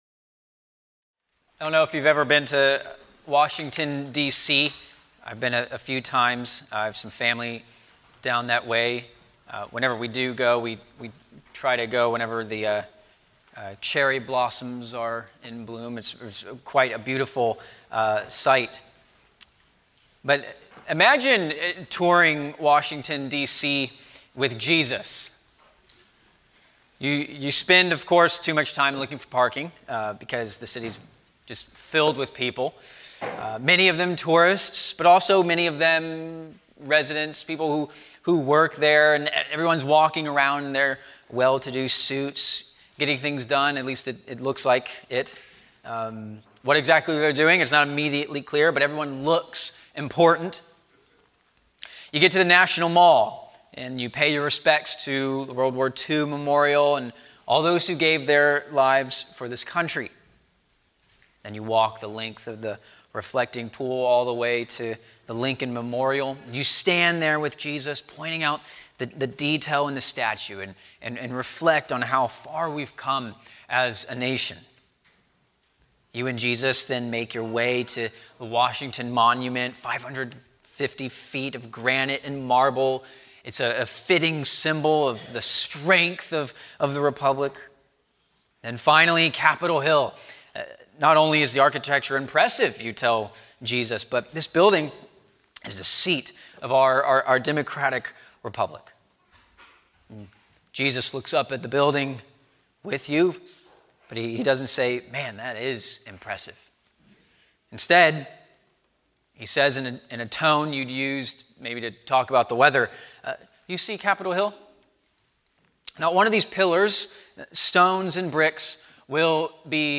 A sermon on Luke 21:25-33